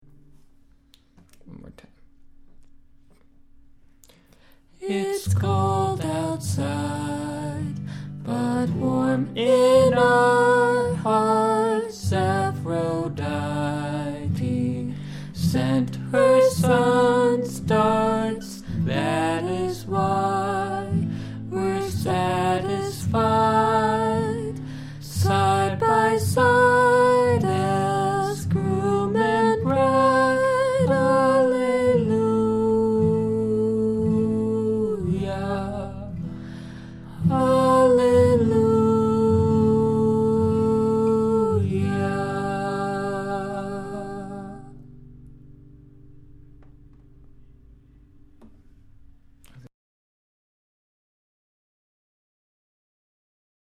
it's in G major
We recorded it when we got home.
inner rhymes are the best. inside/rodit totally made my day. i really like this but i'm not 100% sold on the melody at "groom and bride" for some reason. i think i expect that lift to end differently? i think i expect "groom" to be a different note somehow. awesome. mono, though. you should stereo it up! you might be able to set whatever you're using to record each track in stereo, or at least change the file format to stereo after you mix it down? dunno, i'm still using software from 1998 or something. yay! october EP!